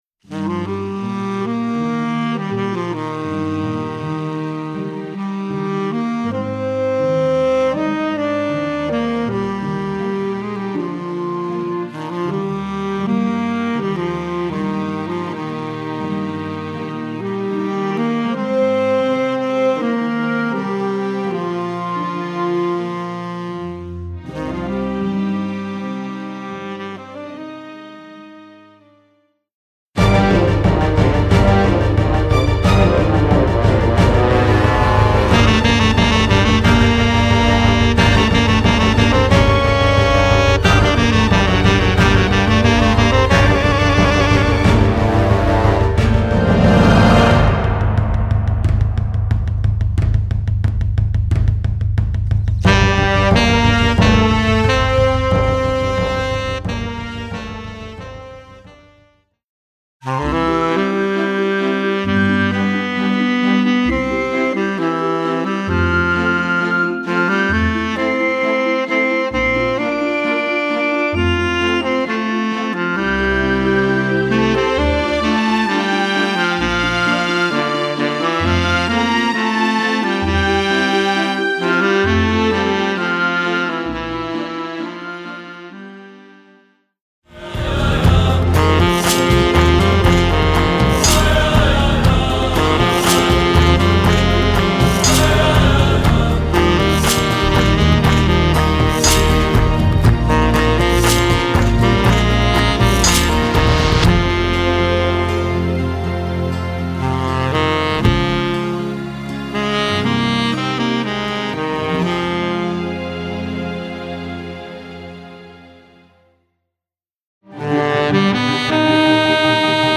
Voicing: t Saxophone w/ Audio